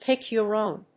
アクセントpíck‐your‐ówn